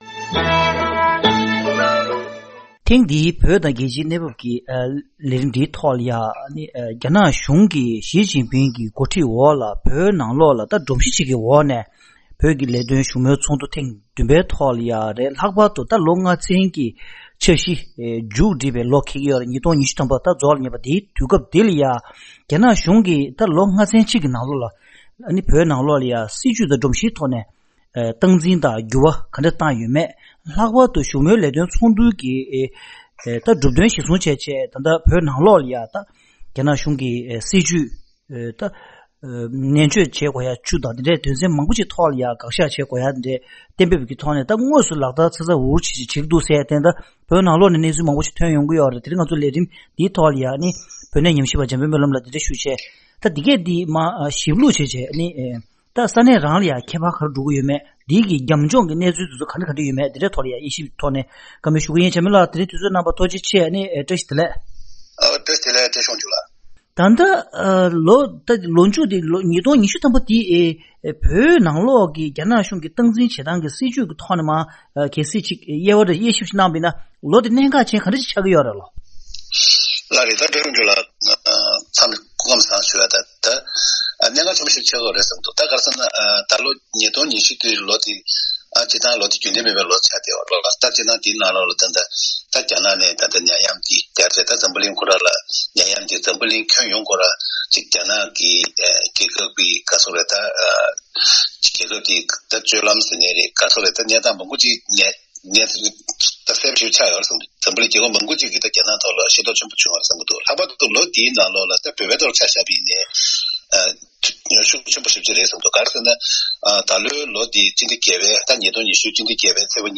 གླེང་མོལ་